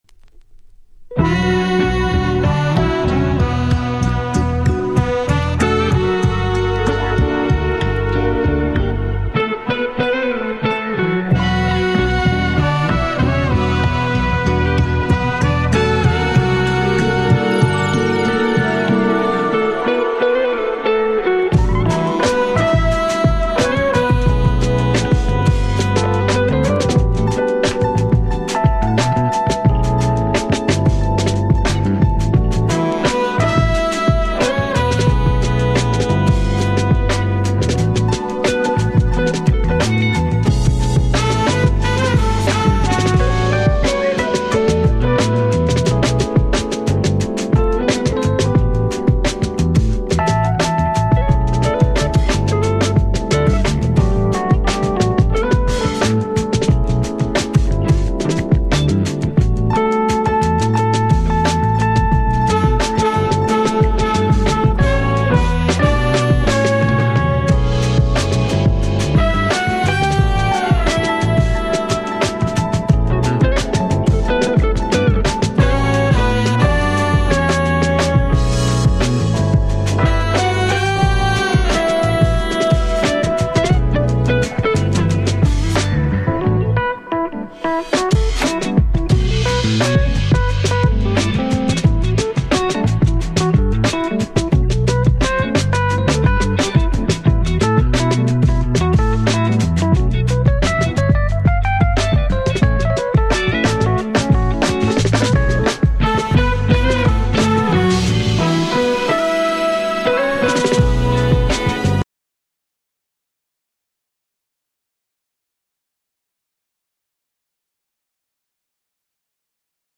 22’ Nice Jazz Cover !!